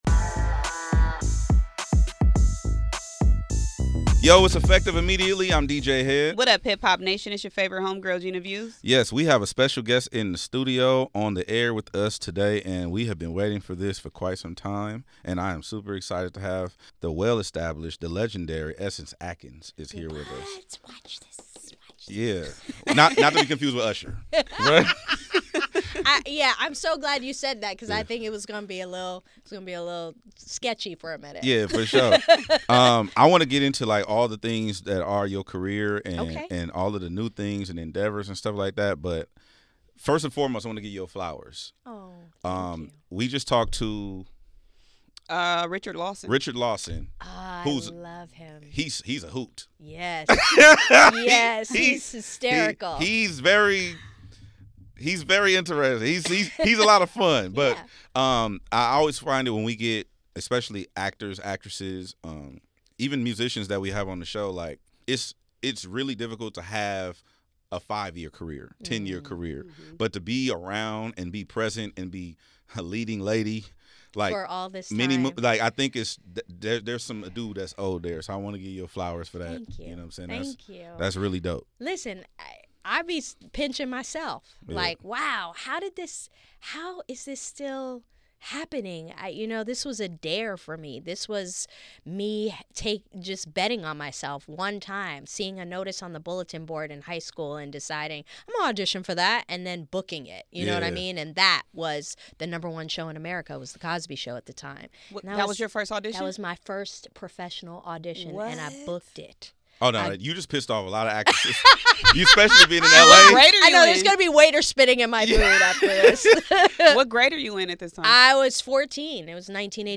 Essence gives an in depth conversation ranging from her first audition, being a part of the legendary UPN Black sitcom run and even the sisterhood she's experienced in the industry.